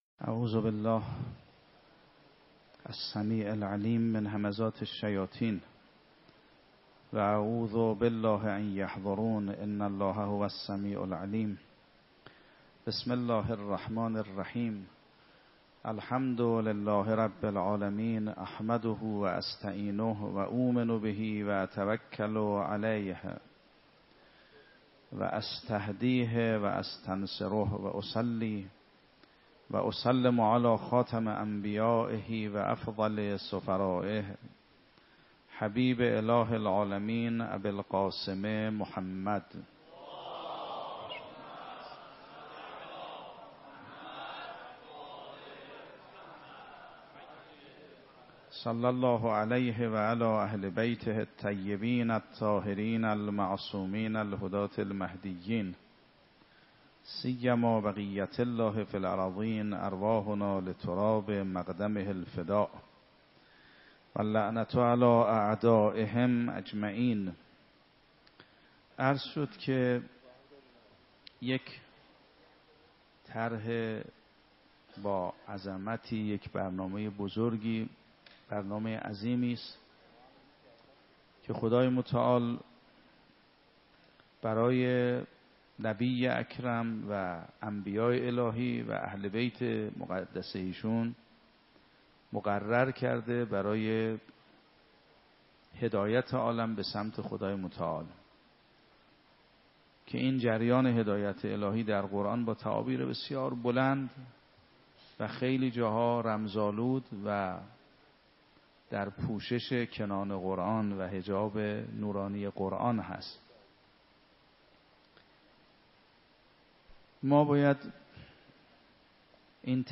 شب نهم رمضان 96 - مسجد ارک - سخنرانی